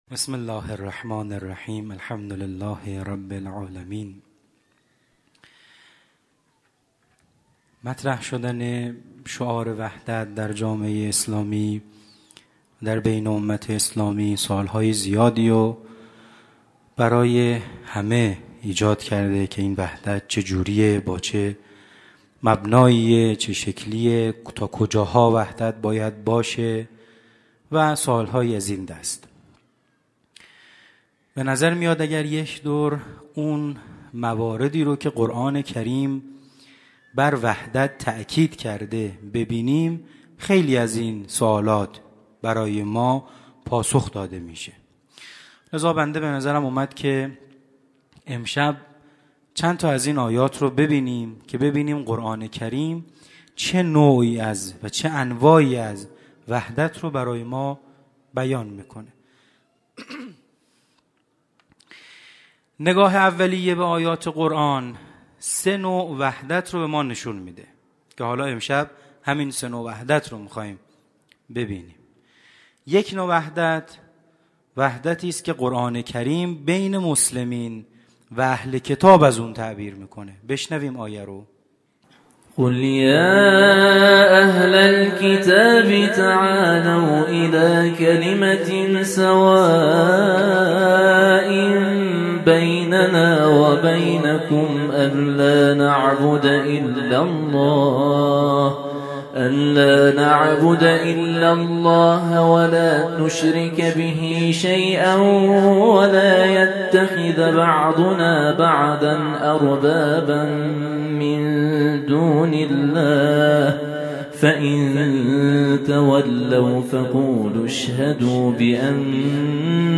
همچنین آیات استناد شده در خلال سخنرانی، توسط یکی از قاریان ممتاز دانشگاه به صورت ترتیل قرائت می‌شود.
در ادامه صوت و پاورپوینت این سخنرانی ارائه می‌شود.